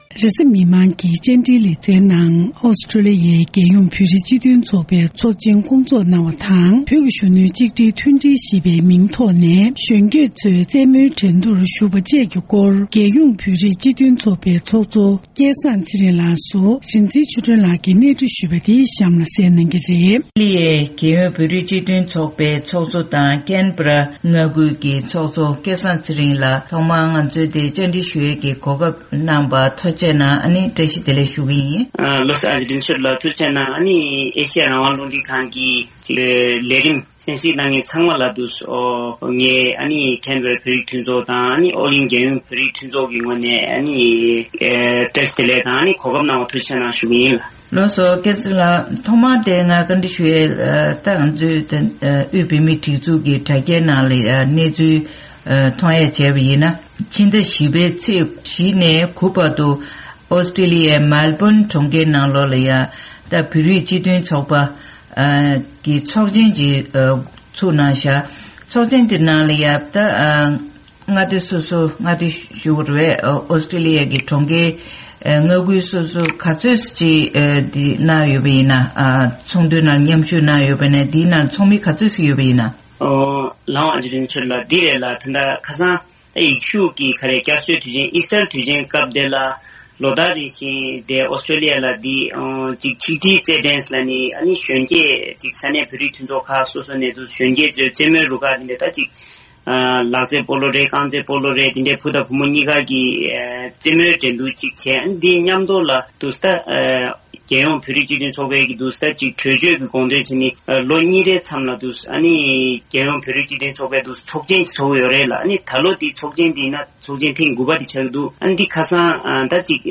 གཟའ་མིག་དམར་གྱི་བཅར་འདྲིའི་ལེ་ཚན་ནང་།